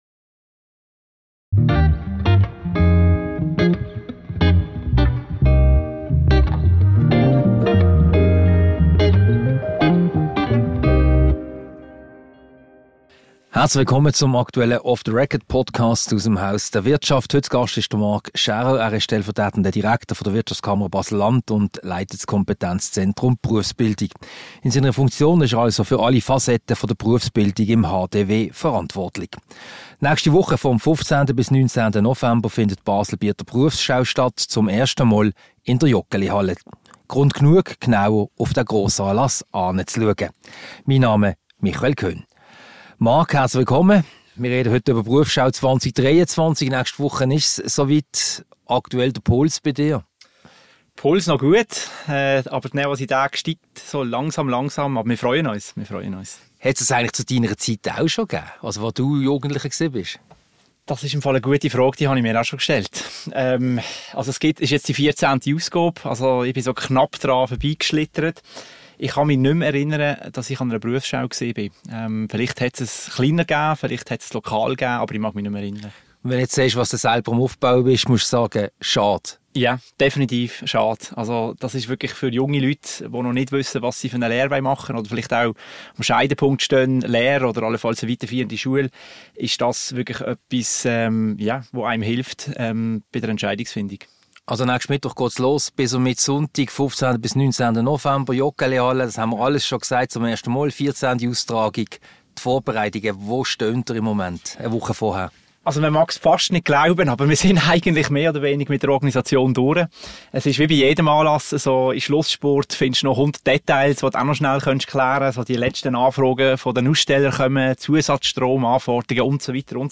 Ein Gespräch über die Berufsschau 2023, die vom 15. bis am 19. November in der St. Jakobshalle stattfindet. Und ein Blick hinter die Kulissen dieser grössten Berufsschau der Schweiz.